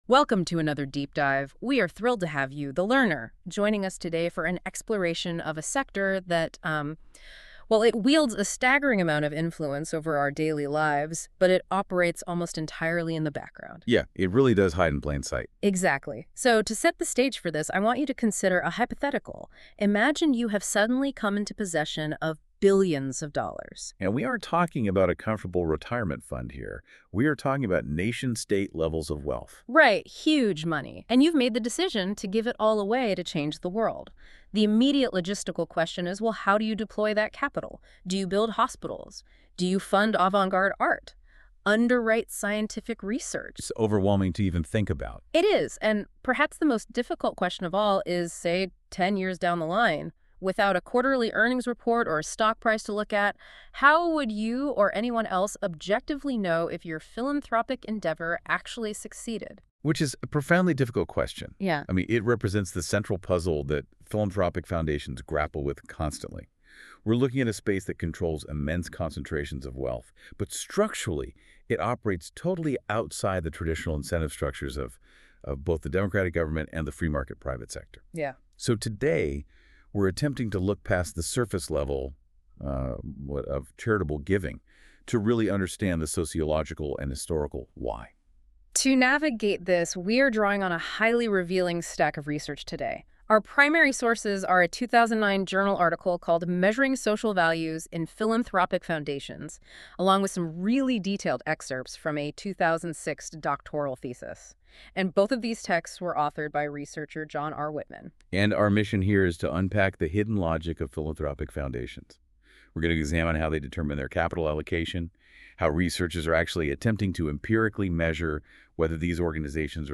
On Philanthropic Foundations in Canada, the United States, and Western Europe Listen to a podcast about how philanthropic foundations engineer society developed by NotebookLM based on the following sources: Whitman, J. R. (2006).